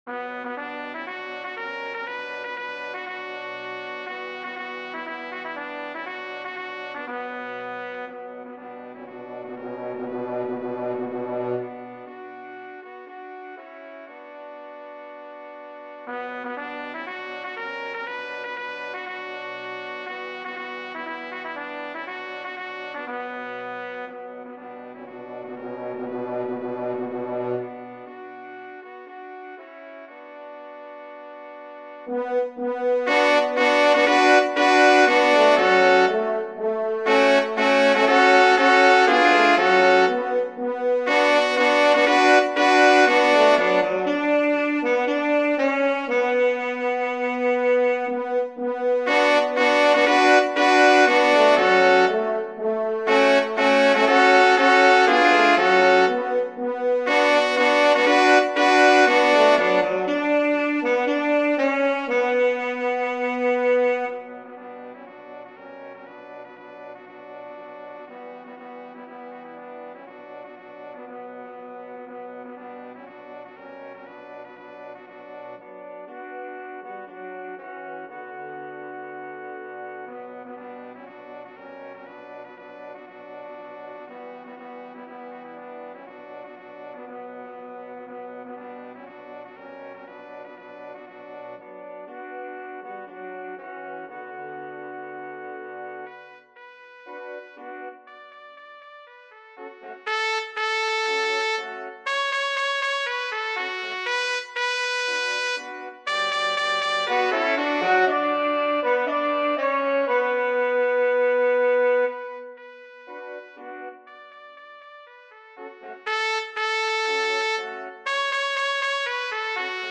chorale thélémites
SOON_AH_WILL_BE_DONE_TUTTI.mp3